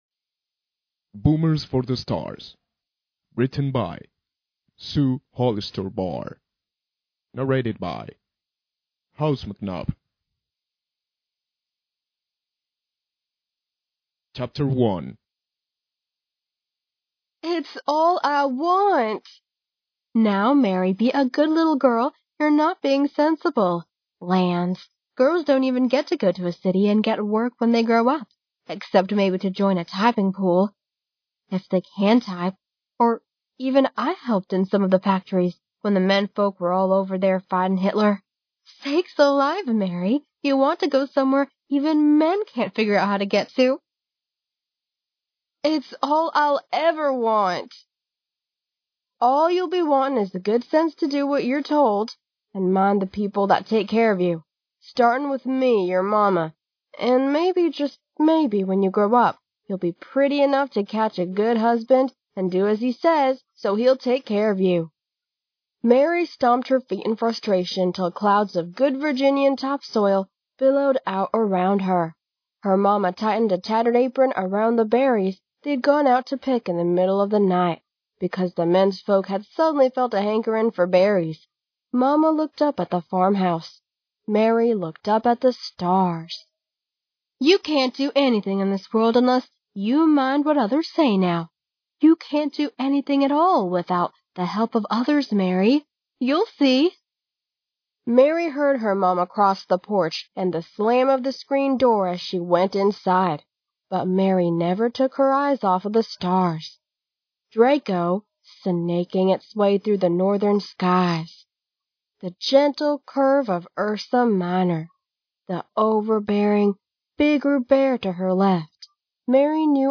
I’m continuing to offer a FREE audiobook version of my 2017 sci fi novella, Boomers for the Stars, which was nominated for a Hugo Award.
BUT BEFORE LISTENING TO THIS AUDIOBOOK: I should perhaps warn you that, with all due respect, the female narrator sounds at times like she thinks she’s reading a children’s book.